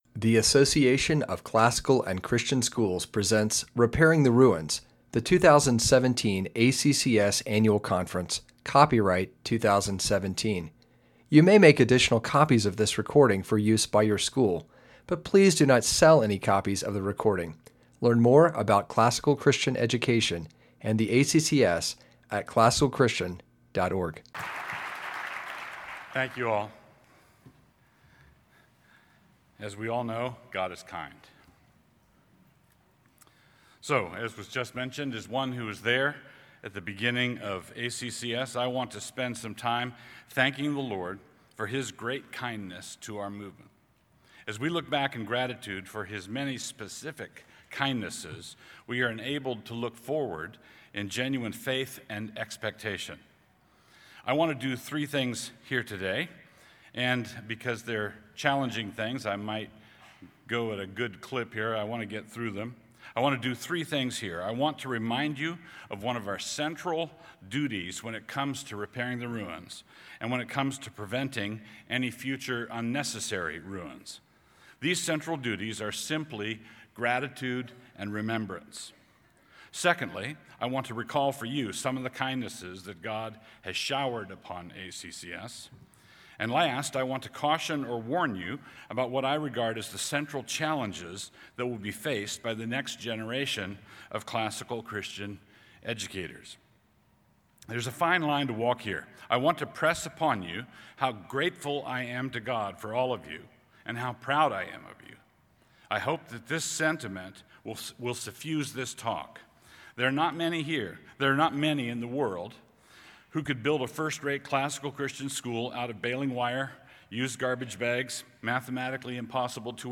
2017 Plenary Talk | 0:45:35 | Culture & Faith, Virtue, Character, Discipline
He is the author of numerous books on classical Christian education, the family, and the Reformed faith Additional Materials The Association of Classical & Christian Schools presents Repairing the Ruins, the ACCS annual conference, copyright ACCS.